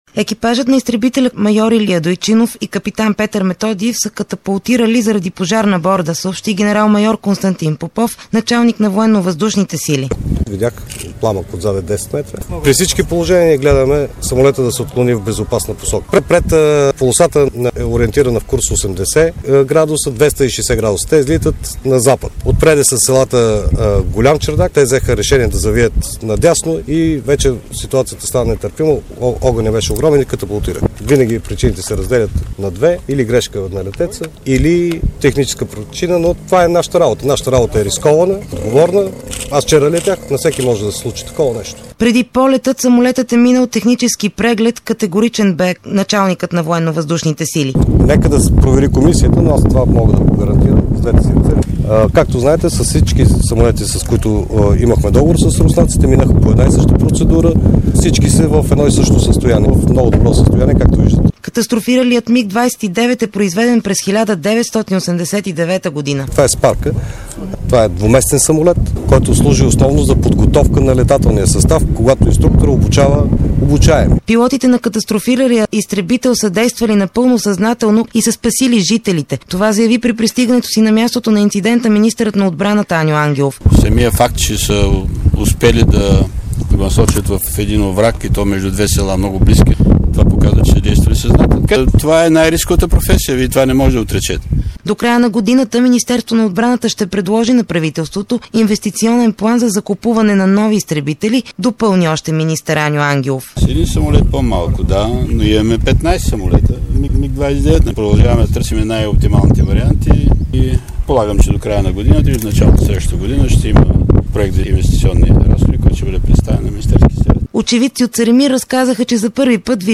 Репортаж от мястото на инцидента